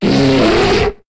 Cri de Pandarbare dans Pokémon HOME.